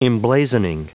Prononciation du mot emblazoning en anglais (fichier audio)
Prononciation du mot : emblazoning